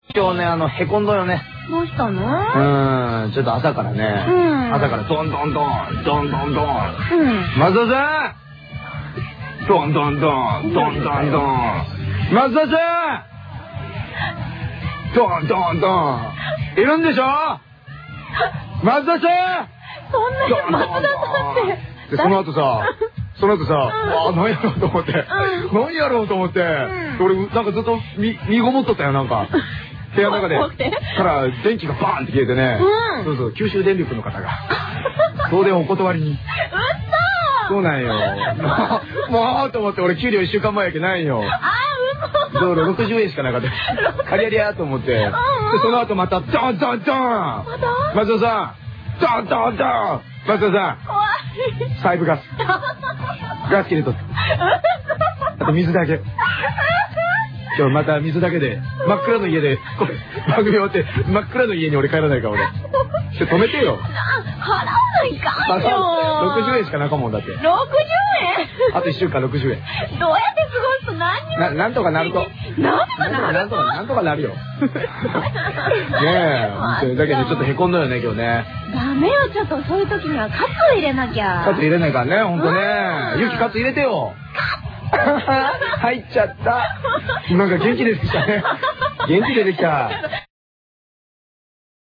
厳選トーク集